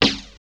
62 SNARE 3-R.wav